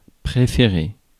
Ääntäminen
IPA: [pʁe.fe.ʁe]